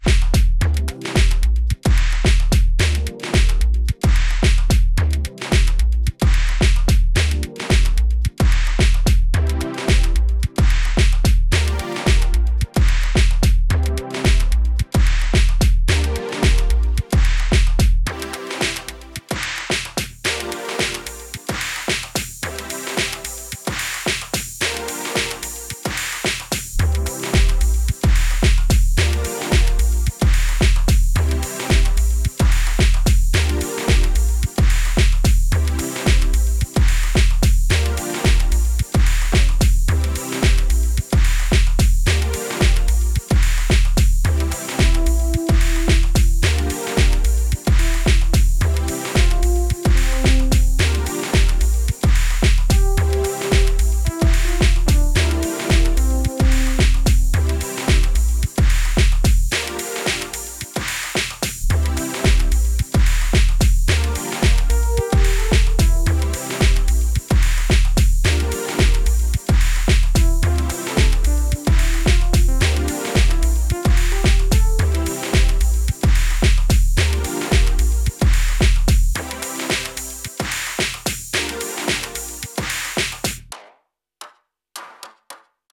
More OT beatstuff. Sampled a snaresound I made with the Volca Drum and and took it from there. 707 on pad support and 808 (ish) hat duty.